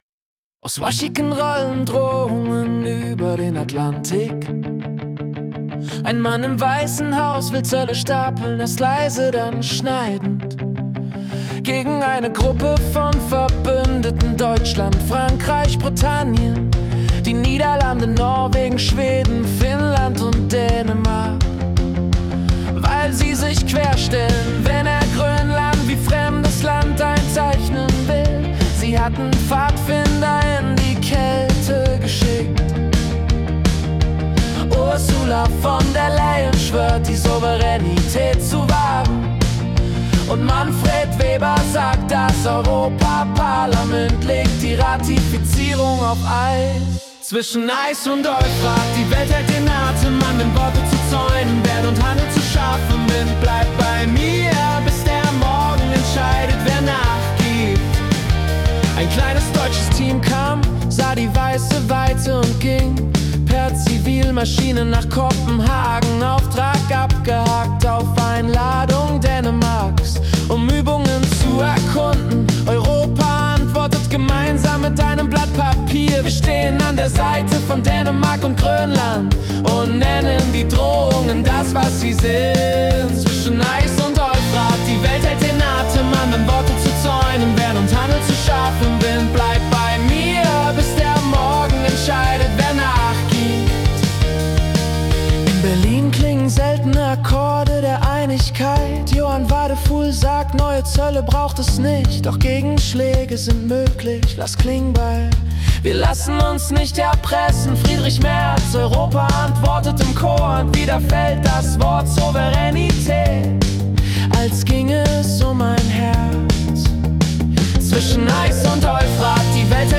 Januar 2026 als Singer-Songwriter-Song interpretiert.